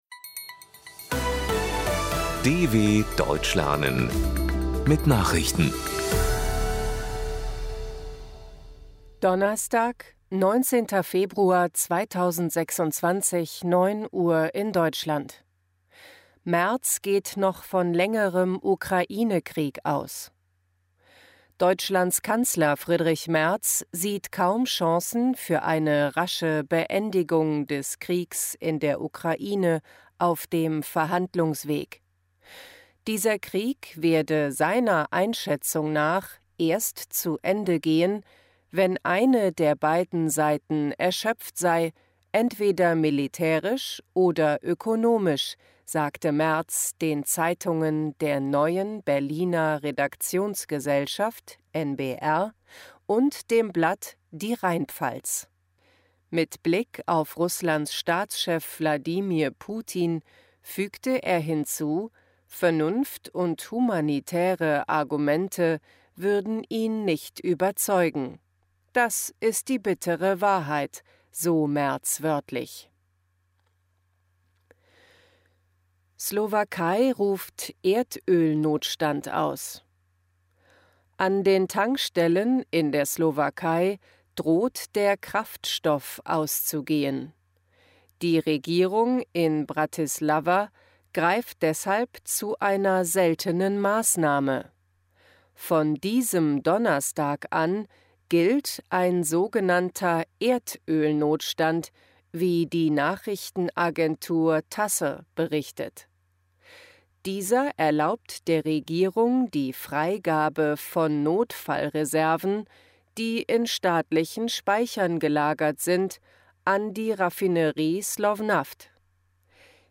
19.02.2026 – Langsam Gesprochene Nachrichten
Trainiere dein Hörverstehen mit den Nachrichten der DW von Donnerstag – als Text und als verständlich gesprochene Audio-Datei.